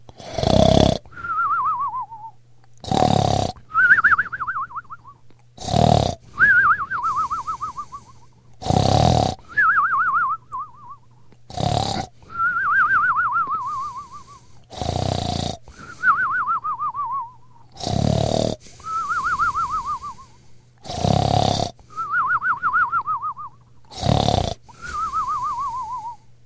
Звуки дыхания, храпа